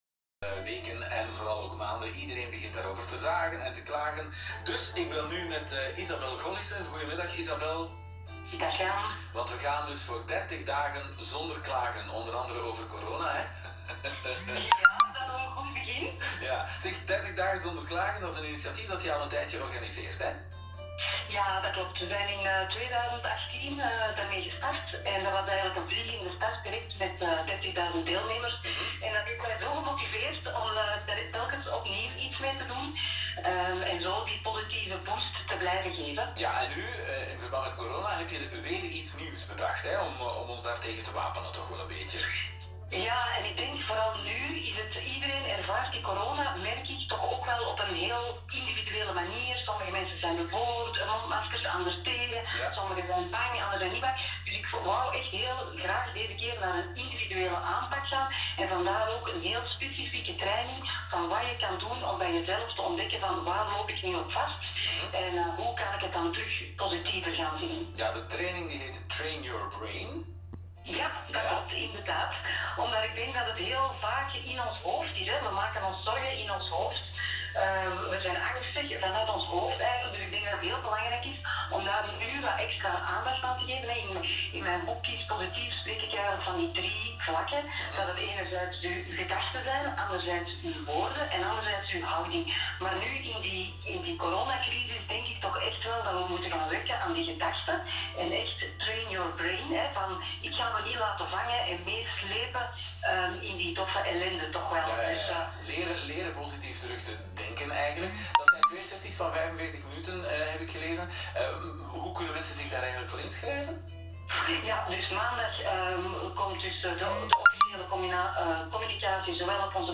Lancering van Train your Brain op radio Joe.